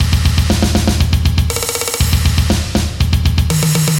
重金属大鼓
描述：重金属鼓。从Dubstep和Electro有点变化。
Tag: 145 bpm Heavy Metal Loops Drum Loops 1.15 MB wav Key : Unknown